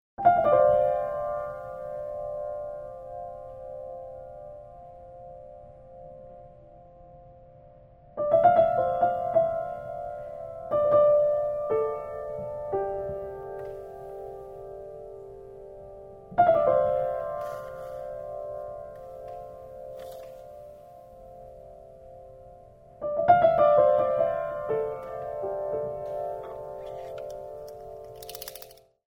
Recorded Live in Japan